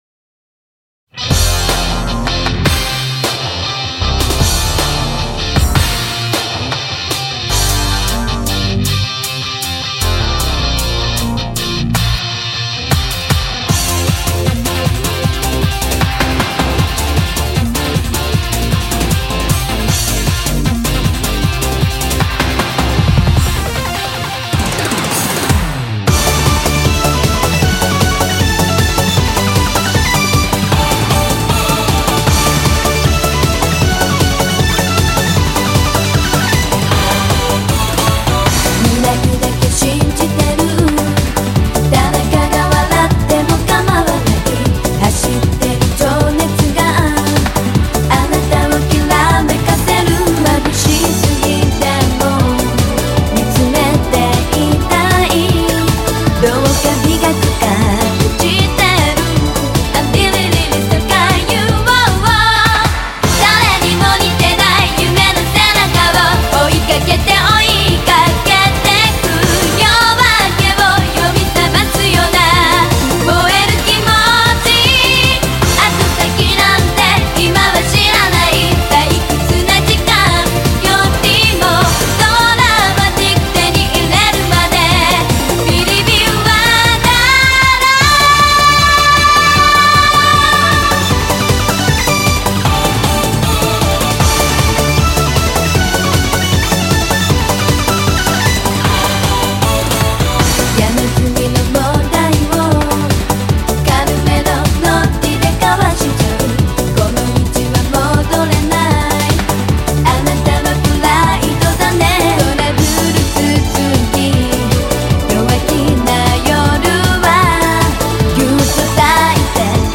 Genre: Anime